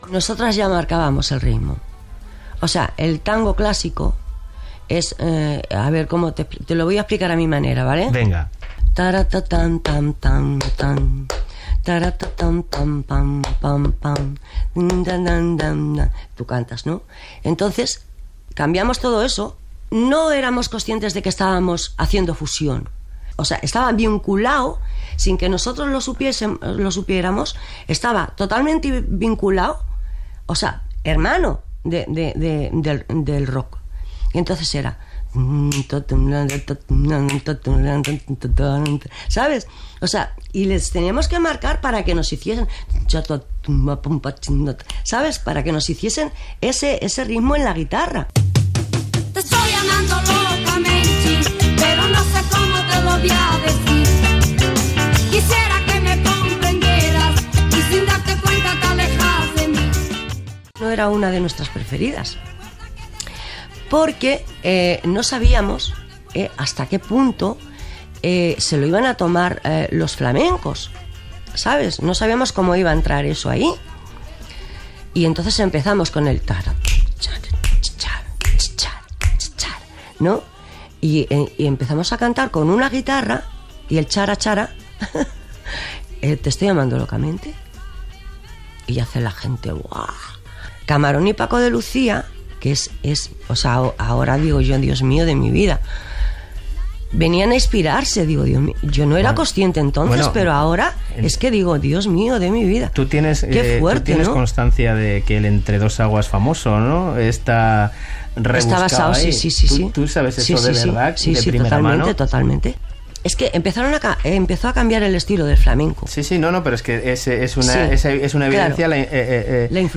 Entrevista a Carmela Muñoz, del duet Las Grecas, sobre la base rítmica de les seves primeres cançons (1974) i la influència que aquestes van tenir en altres artistes